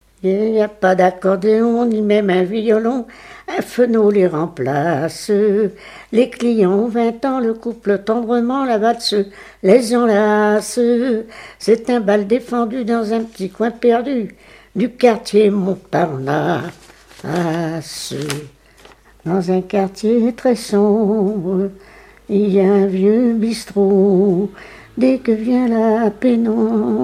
répertoire familiale de chansons
Pièce musicale inédite